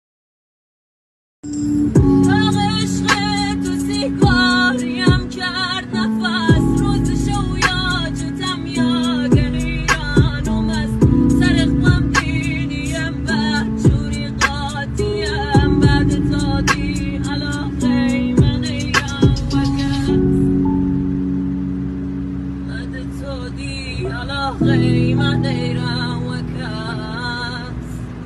واقعا صداش پر از غمه💔🥀🖤